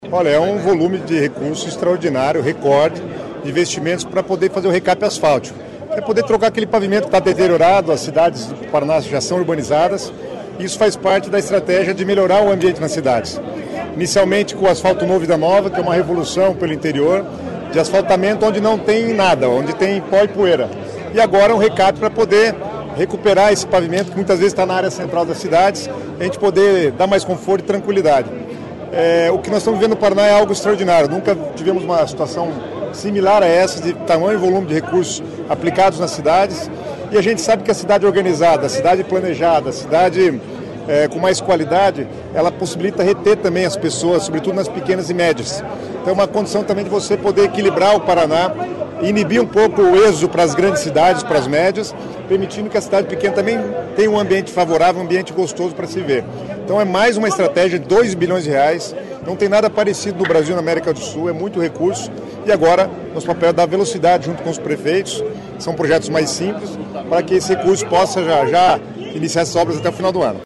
Sonora do secretário de Estado das Cidades, Guto Silva, sobre o repasse de R$ 2 bilhões para os municípios para projetos de recapeamento asfáltico | Governo do Estado do Paraná